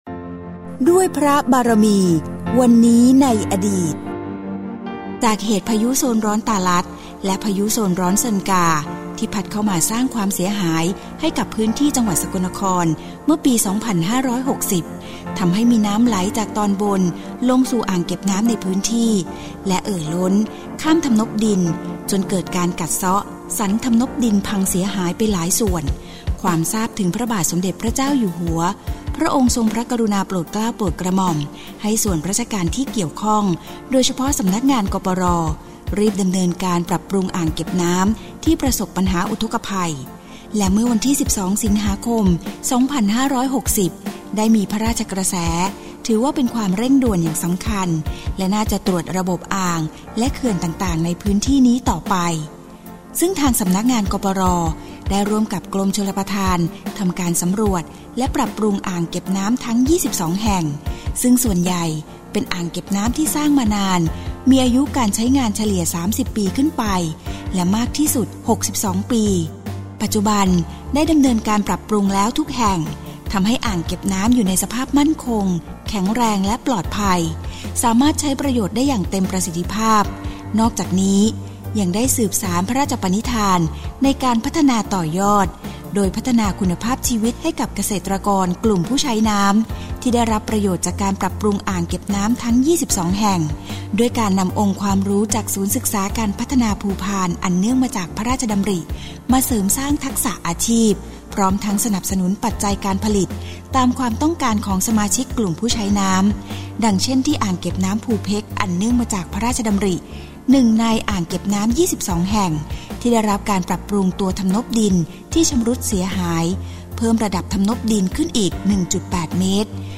สารคดี